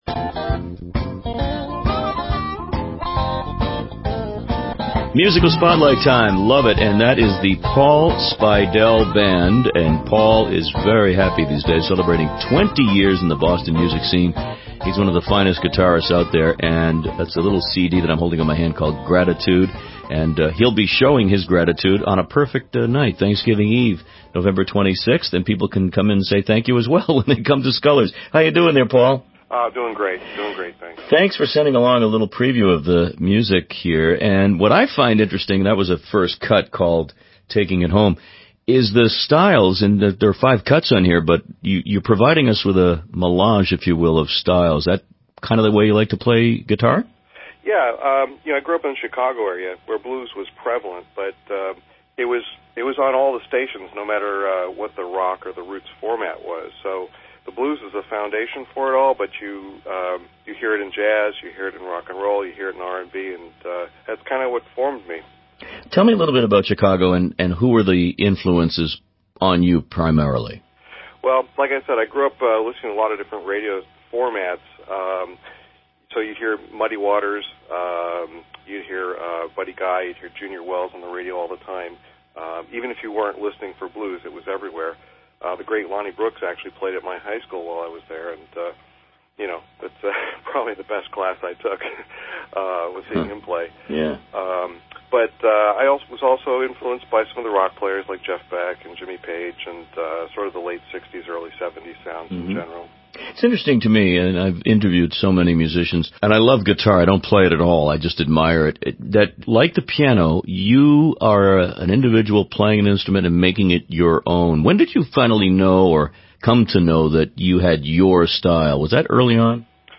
wbz interview